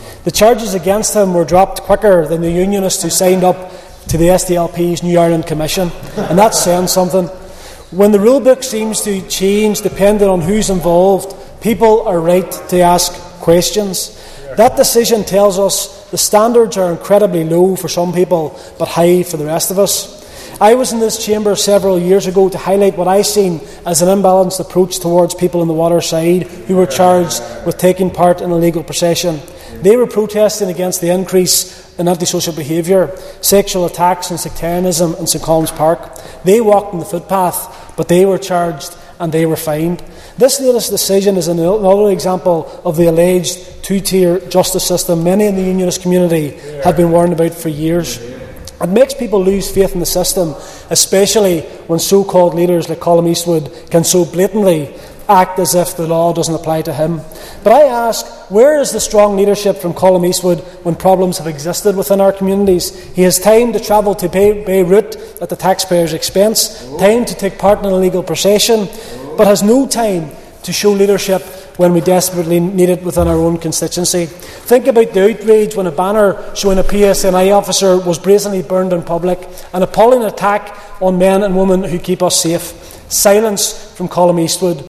Foyle MLA Gary Middleton has criticised the constituency’s MP Colum Eastwood in the Stormont Assembly.